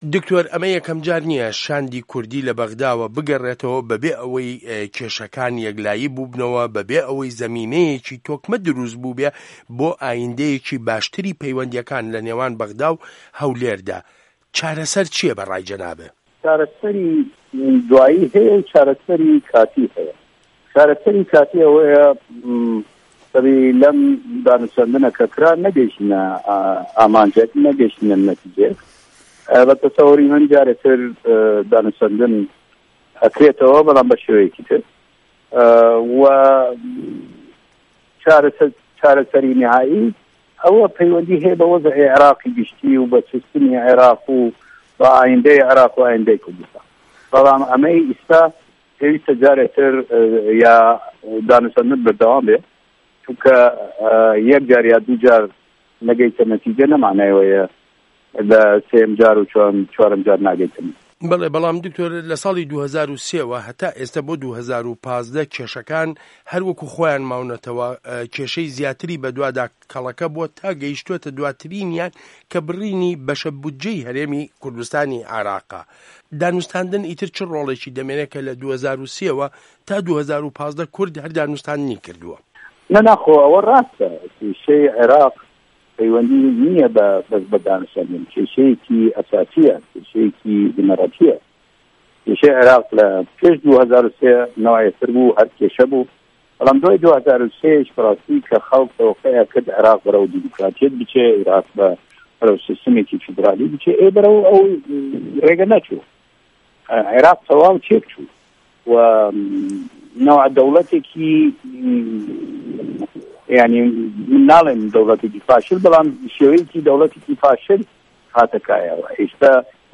وتووێژ له‌گه‌ڵ دکتۆر فوئاد حوسه‌ین